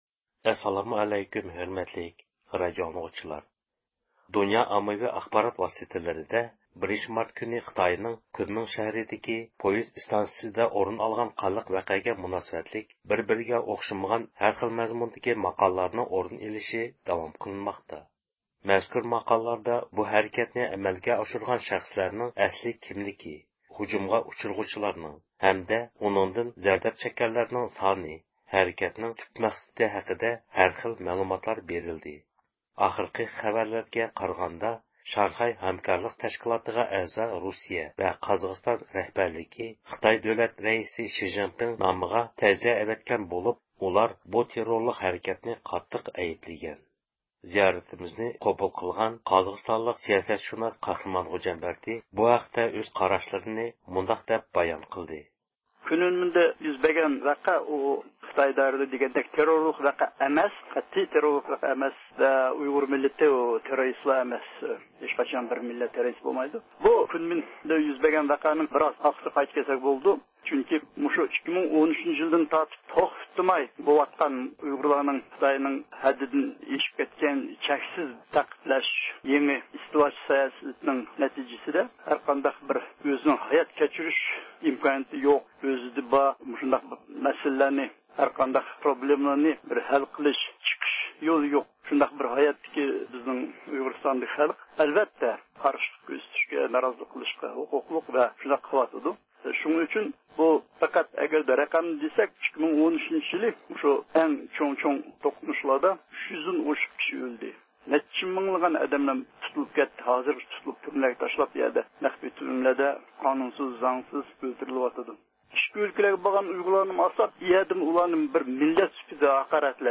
رادىئومىز زىيارىتىنى قوبۇل قىلغان سىياسەتشۇناس